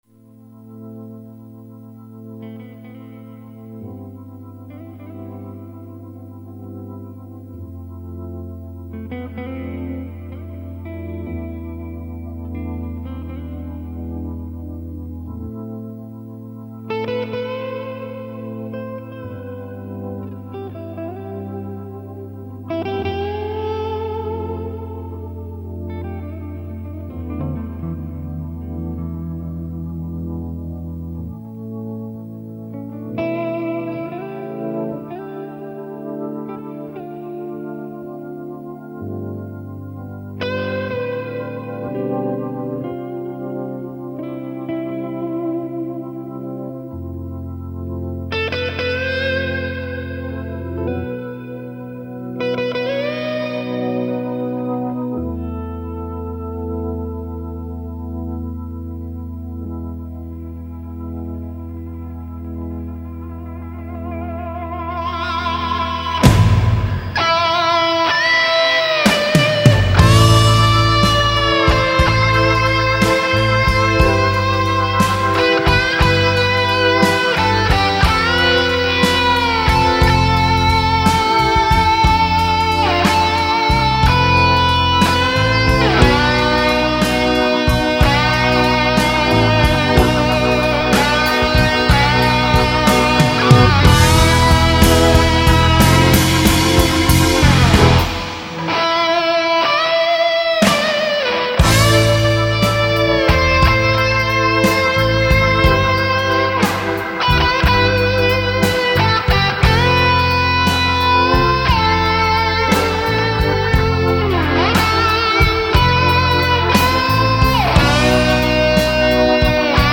제가 아주아주 우울할 때 듣는 곡입니다.
정말 슬픈 감정이 폭발하는.. 그런 느낌이 듭니다.